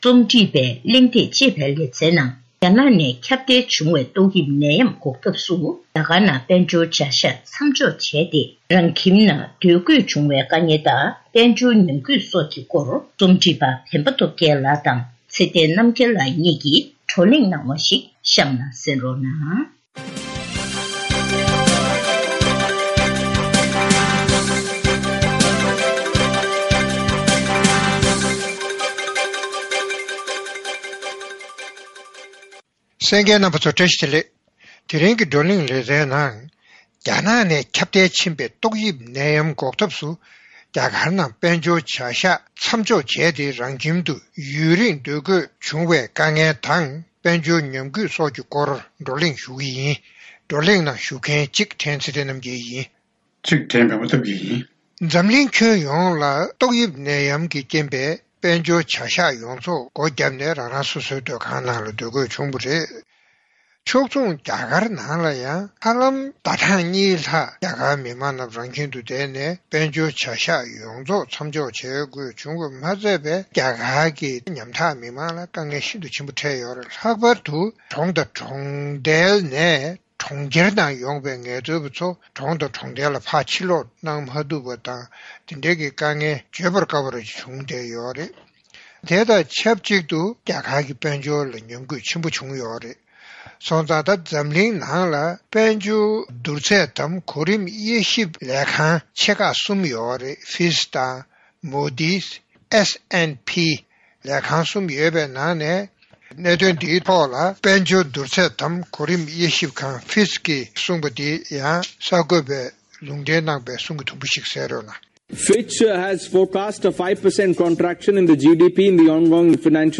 བགྲོ་གླེང་གནང་བ་གསན་རོགས།།